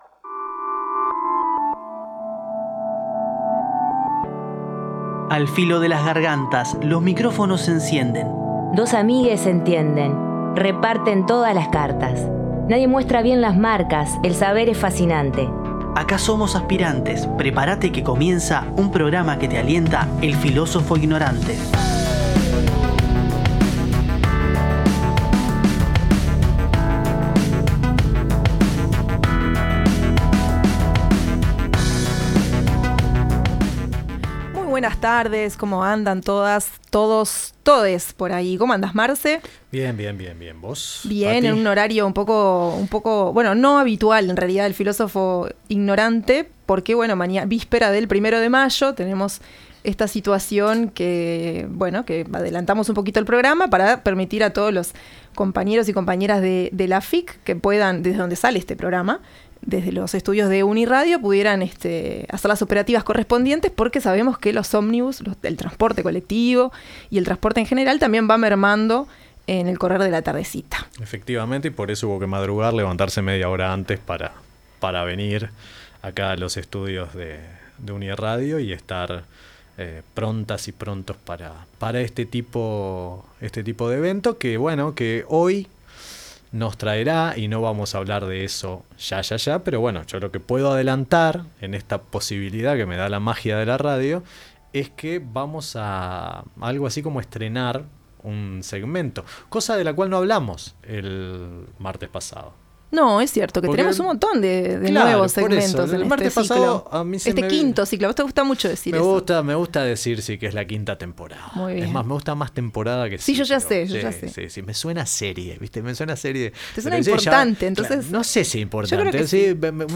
En El Filósofo Ignorante inauguramos un nuevo segmento llamado «Pico a pico» en el que compartiremos conversaciones con distintas personas sobre diversos temas.